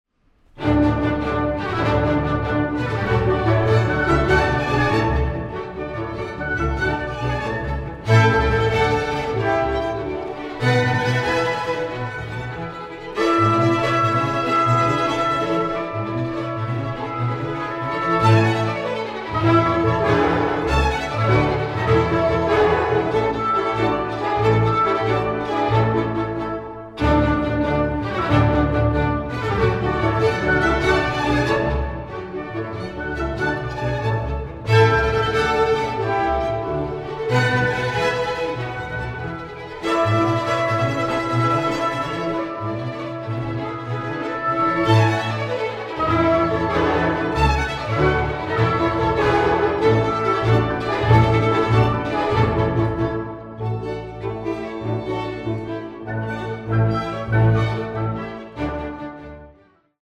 Presto